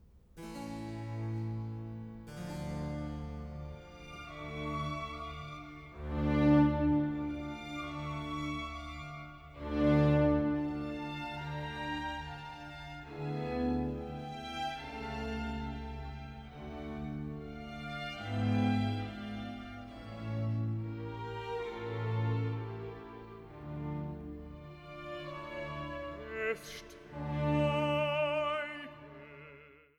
Passionskantate für Soli, Chor und Orchester
Recitativo (Bass) „Auf einmal fällt der aufgehaltne Schmerz“